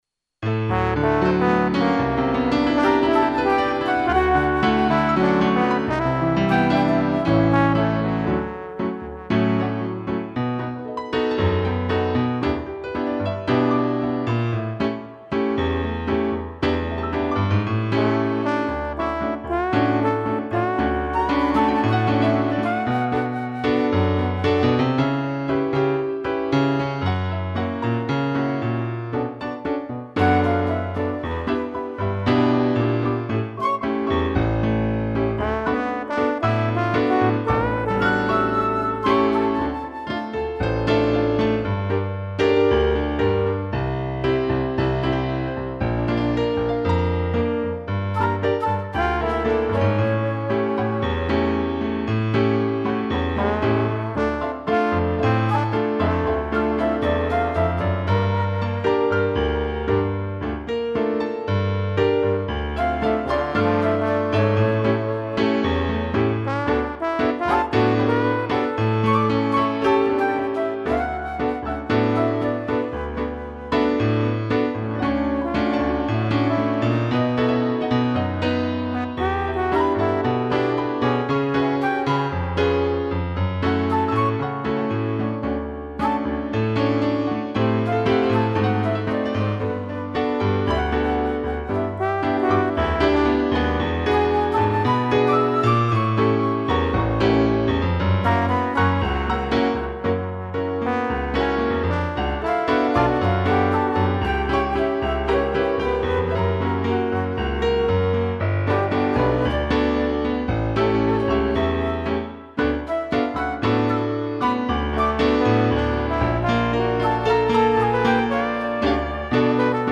2 pianos, flauta e trombone
(instrumental)